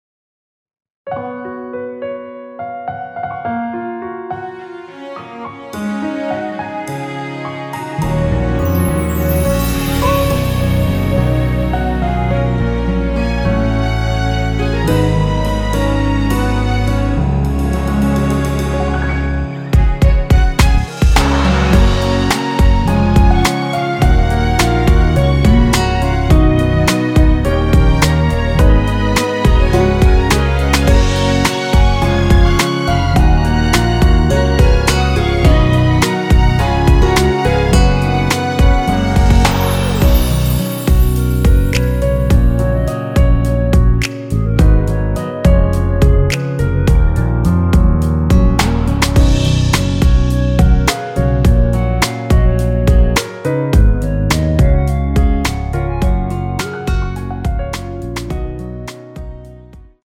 원키에서(-2)내린 멜로디 포함된 MR입니다.(미리듣기 확인)
F#
앞부분30초, 뒷부분30초씩 편집해서 올려 드리고 있습니다.
중간에 음이 끈어지고 다시 나오는 이유는